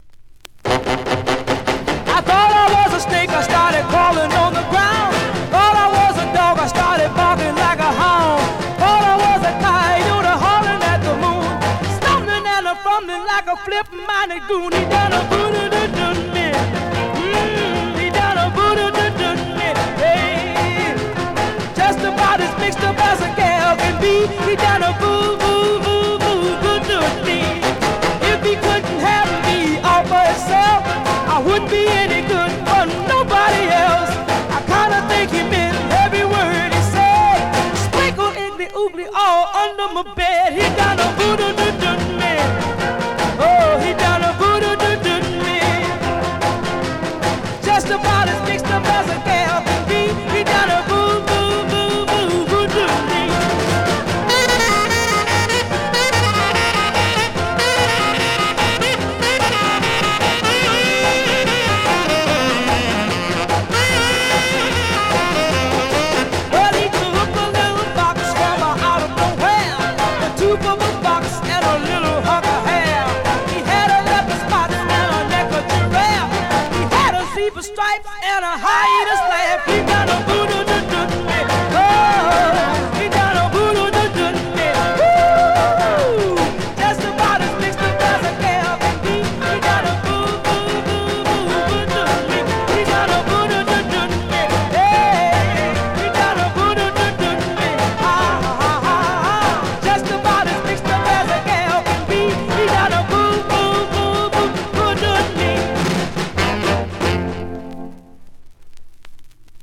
Vinyl has a few light marks plays great .
Great classic up-tempo Rnb / Mod dancer .